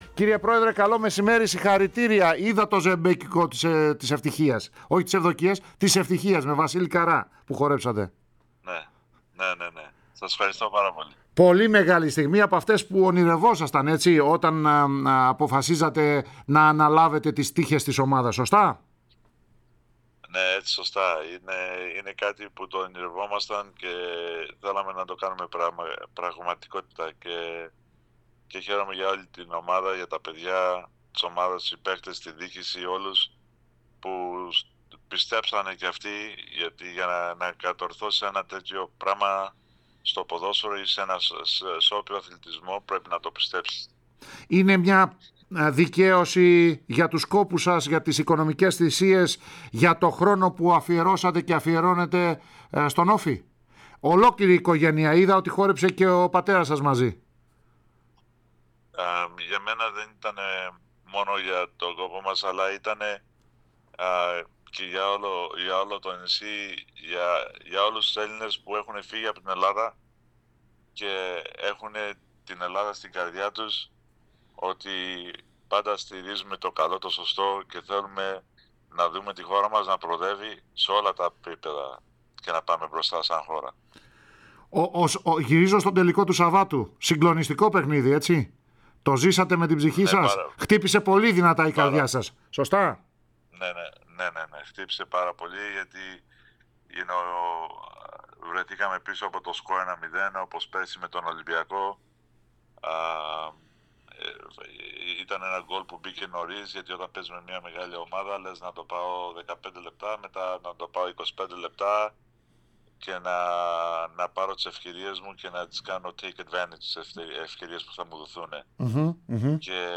φιλοξενήθηκε στον "αέρα" της ΕΡΑ ΣΠΟΡ